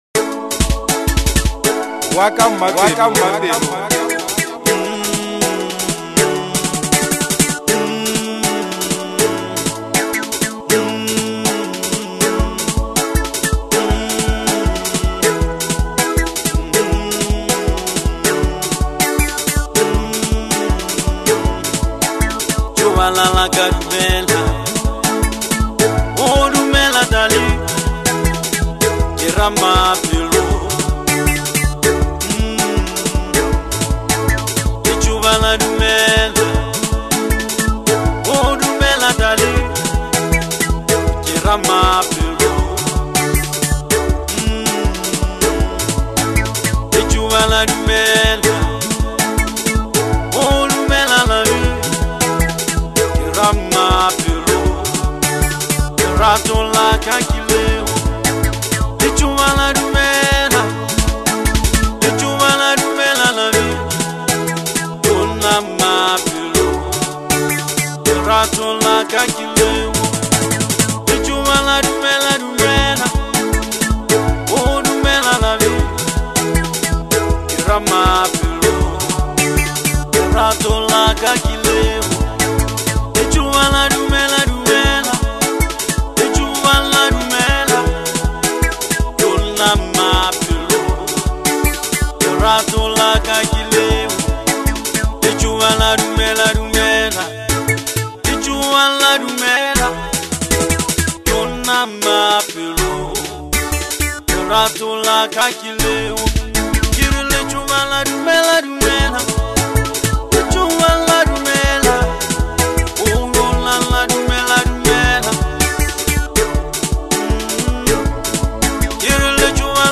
melodious traditional music
Genre : Gqom